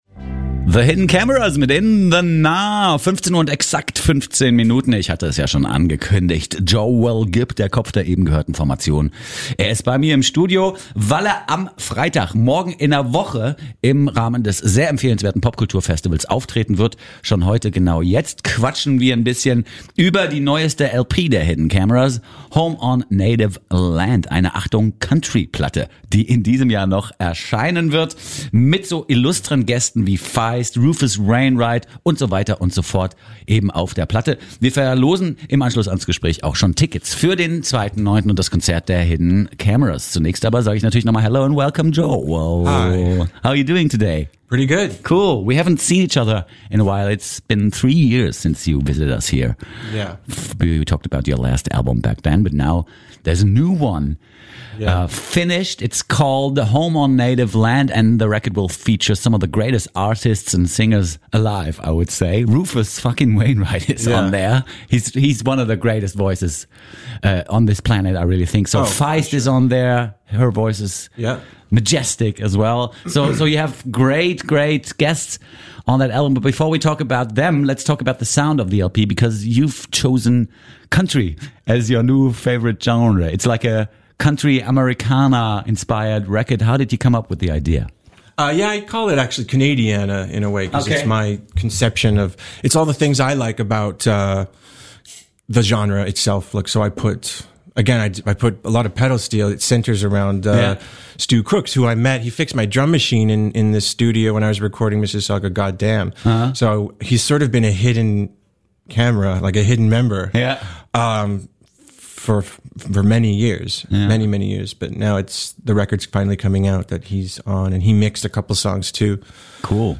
"Gay Church Folk" nennt Joel Gibb seine Musik - und kommt mit neuer Platte, auf der er sich prominente kreative Unterstützung geholt hat, ins FluxFM-Studio zum exklusiven Interview.